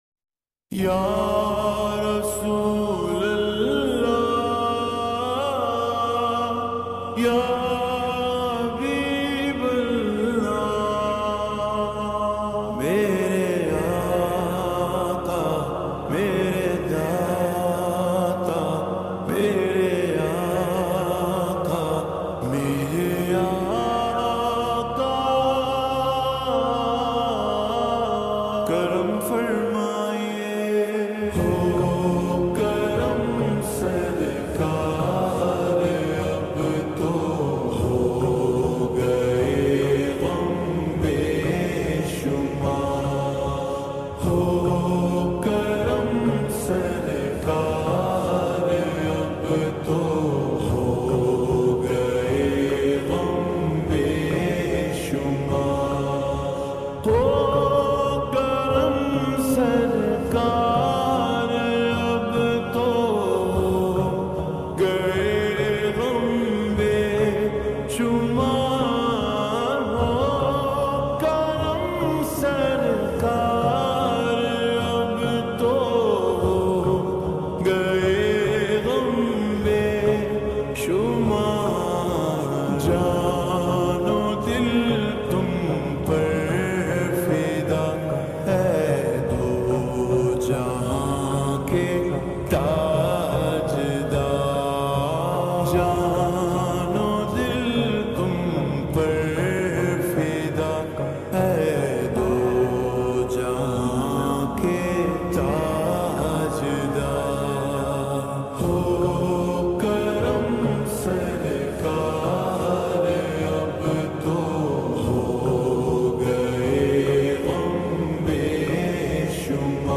Heart Touching Naat